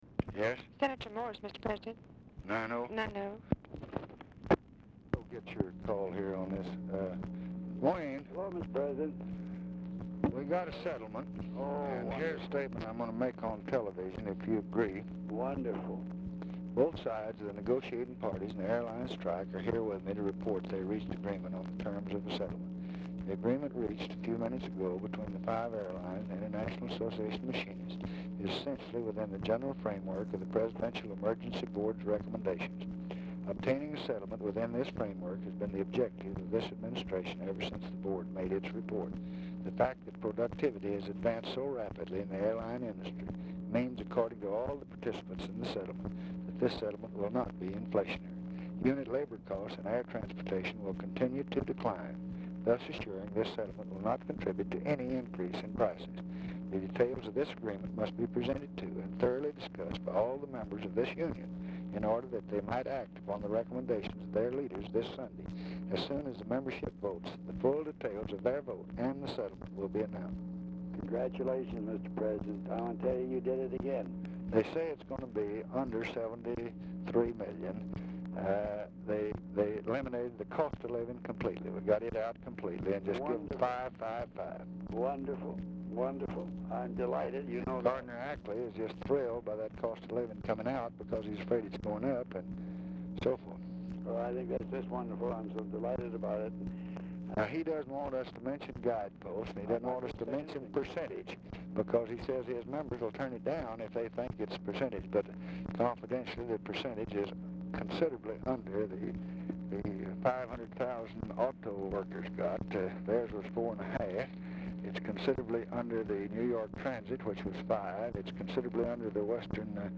Telephone conversation # 10456, sound recording, LBJ and WAYNE MORSE, 7/29/1966, 9:40PM | Discover LBJ
Format Dictation belt
Specific Item Type Telephone conversation